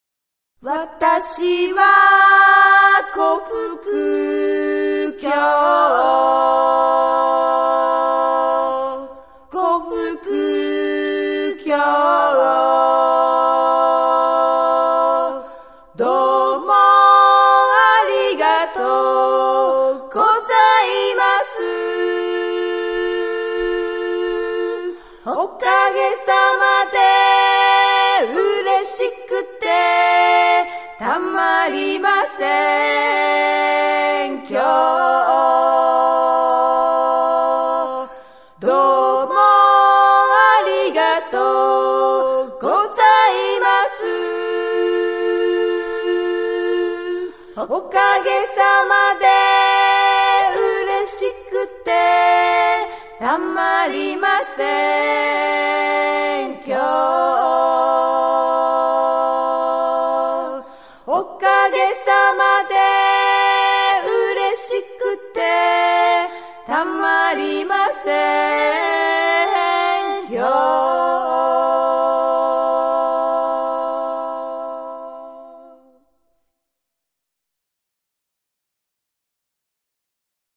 音乐类型：世界音乐
日本 / 冲绳、台湾、太平洋群岛民歌